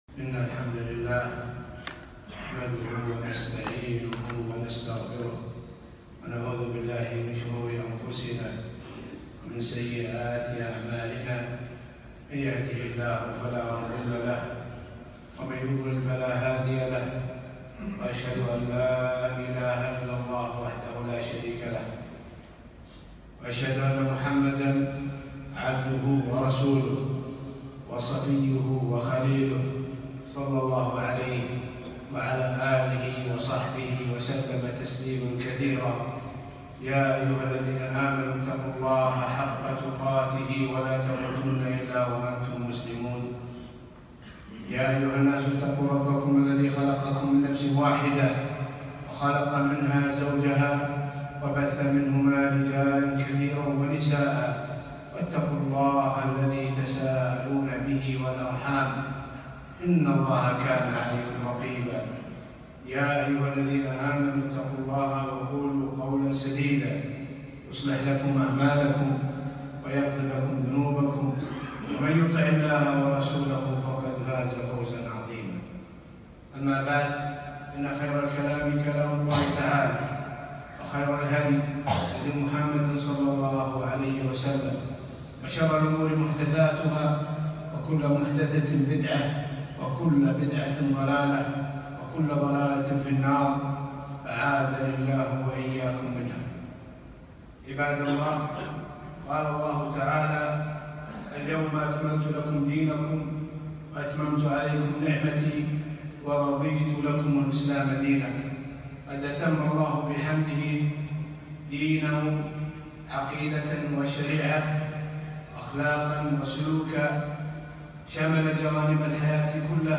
خطبة - مكارم الأخلاق